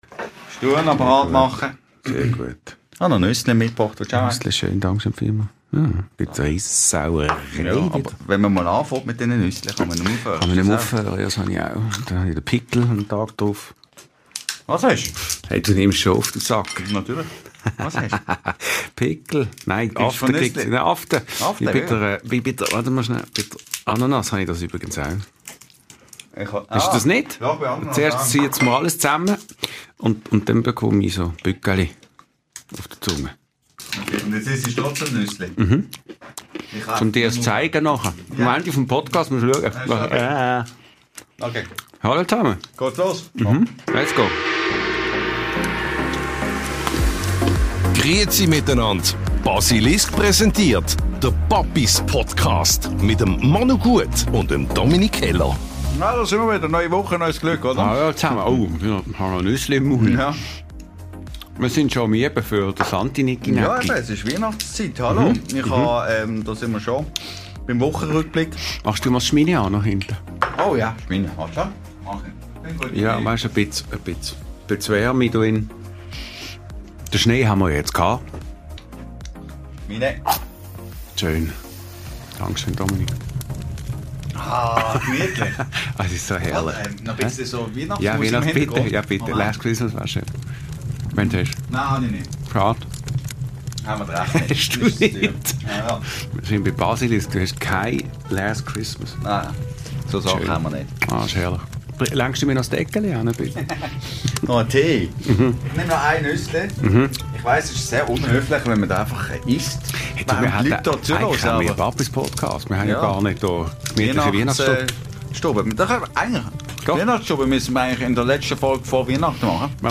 Weiter kritisieren unsere Papis das stetige Vorverurteilen und plädieren für mehr gesunden Menschenverstand. Abgeschlossen wird die Therapierunde von sehr schrägen Geschichten über Helikopter-Eltern und einer kleinen ASMR-Einlage mit Erdnüssen.